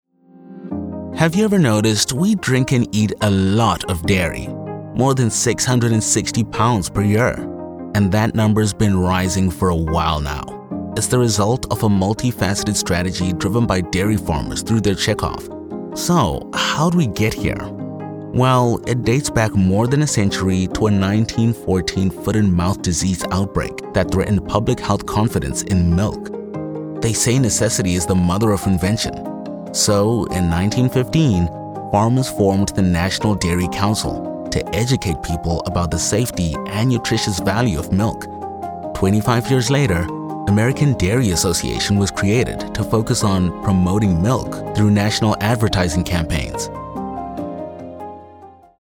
English (South African)
Adult (30-50)